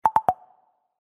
Spotify Pause Sound Effect Free Download